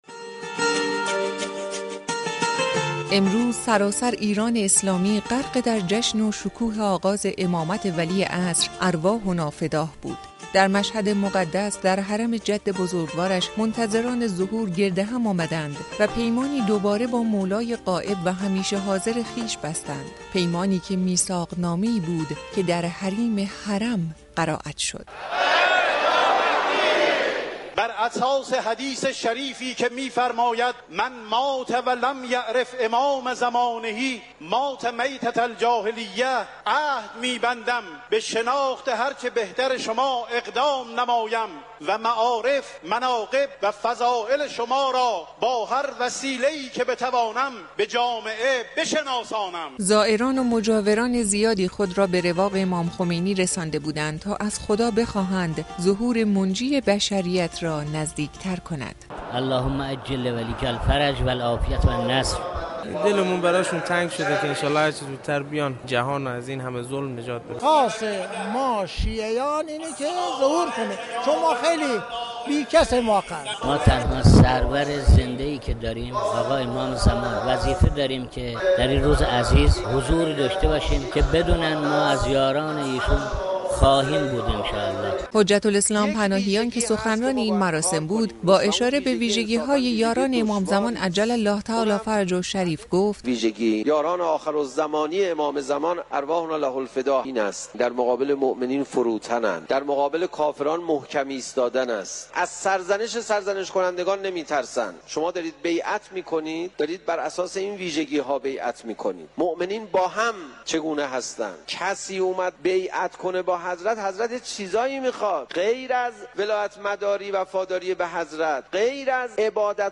حرم مطهر رضوی عصر امروز میزبان زائران و عاشقان مهدی موعود عج بود . این مراسم با نام اجتماع منتظران ظهور و همچنین عید بیعت با امام عصر (عج)، در رواق امام خمینی برگزار شد.